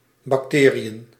Ääntäminen
US : IPA : /bækˈtɪɹ.i.ə/